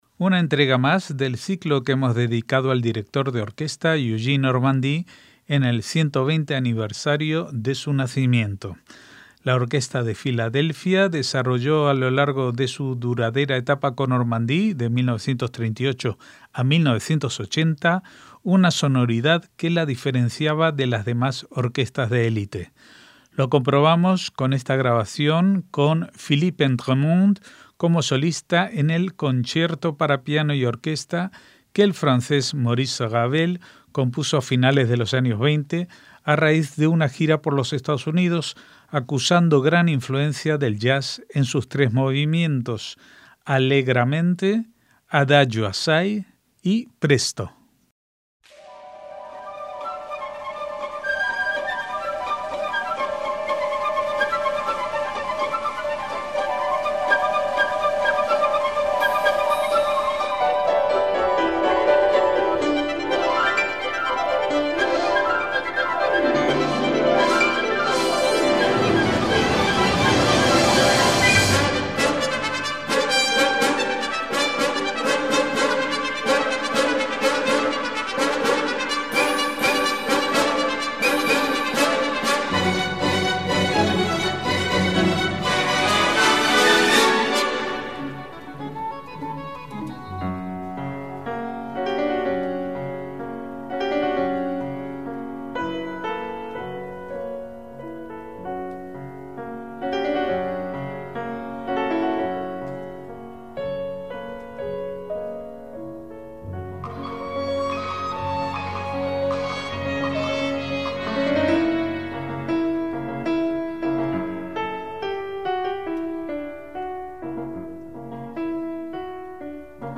MÚSICA CLÁSICA
acusando gran influencia del jazz en sus tres movimientos